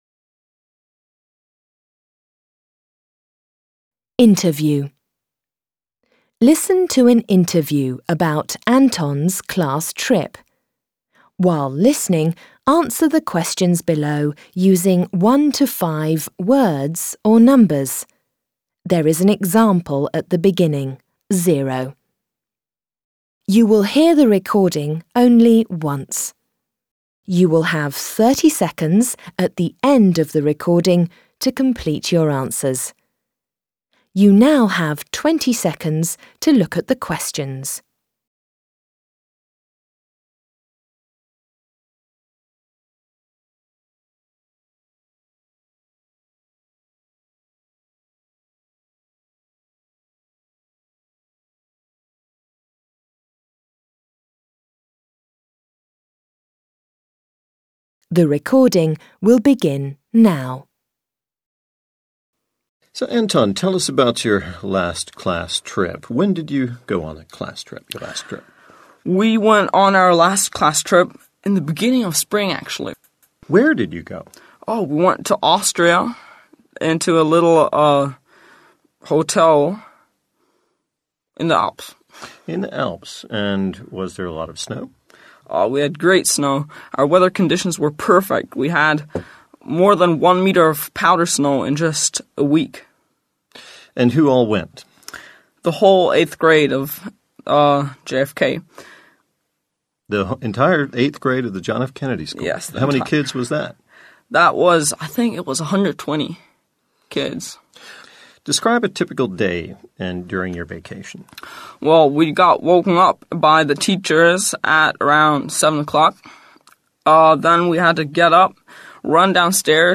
Beispielaufgabe zum Hörverstehen - Interview
Hörimpuls zur Beispielaufgabe - Interview (mp3-Datei)
Beispiel_Hoerimpuls_-_Interview.mp3